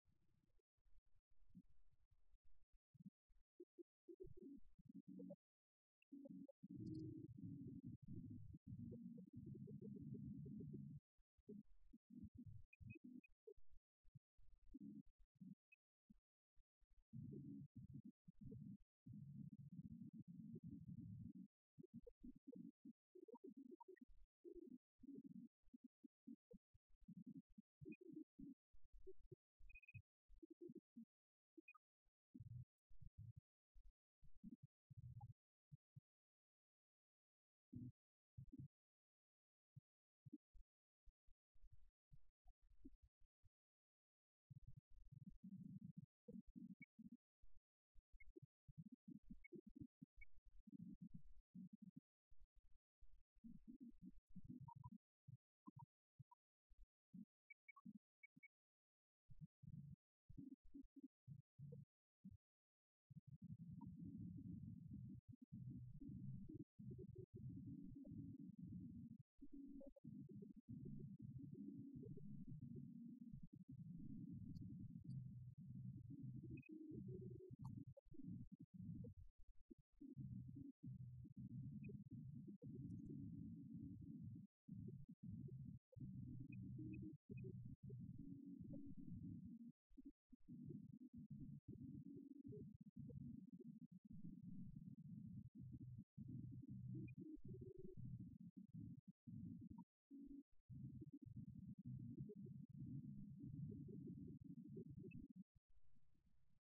mars-indonesia-raya.mp3